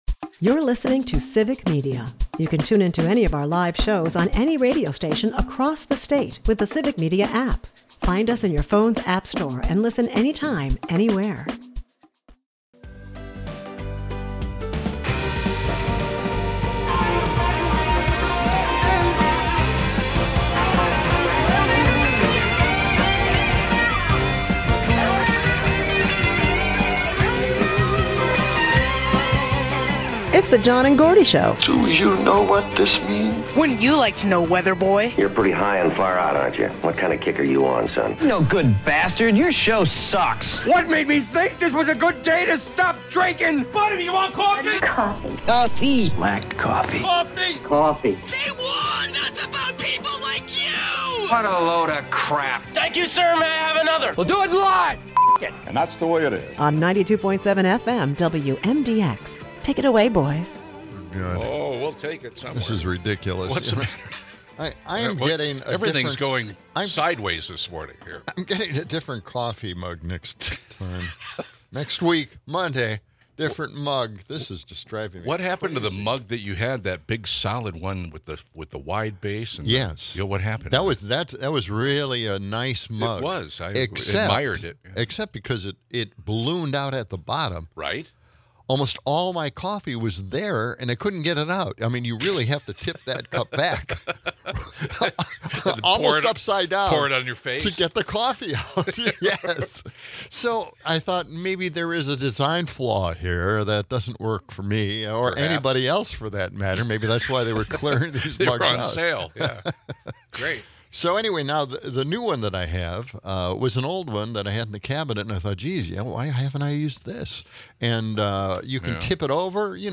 The episode touches on AI's potential pitfalls, like kids seeking dubious advice from chatbots, and ends with local call-ins discussing everything from muskie releases to media bias!